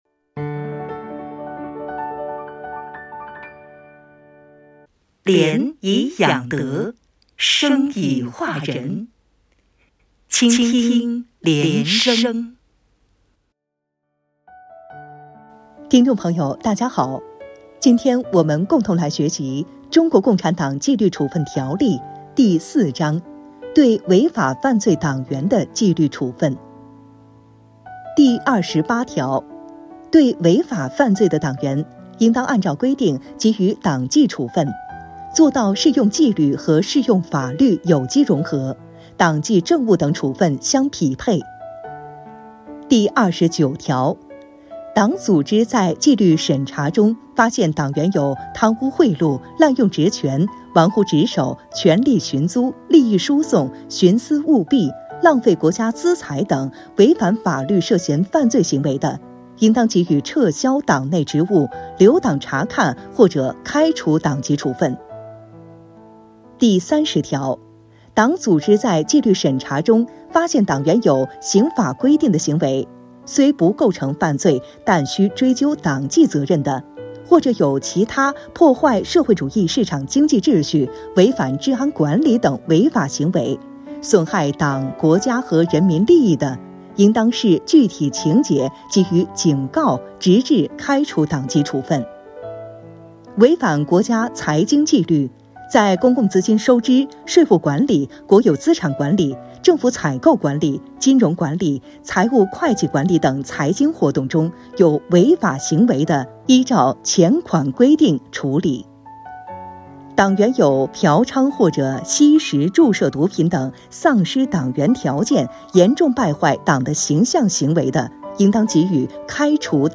原文诵读系列音频